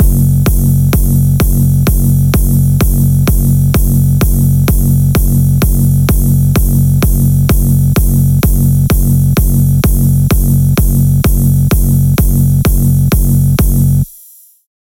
描述：Dance Electronic|Happy
标签： Synth Drums
声道立体声